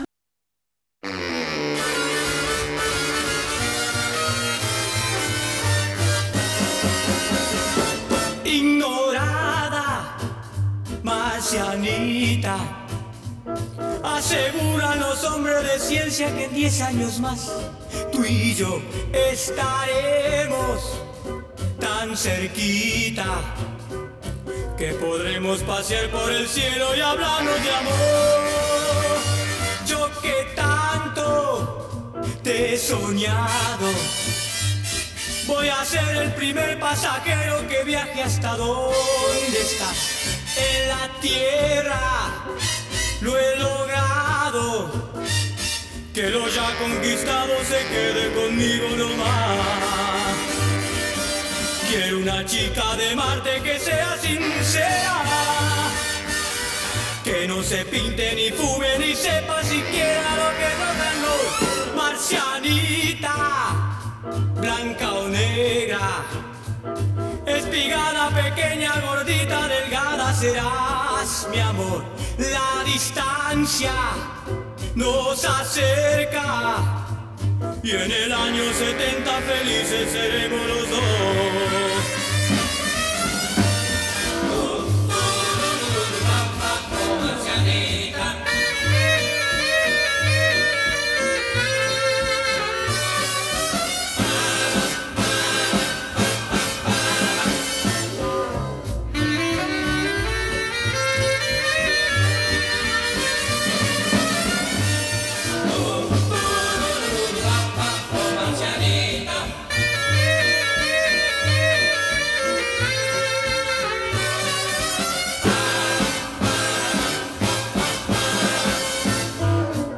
Eran los principios del rock and roll en español.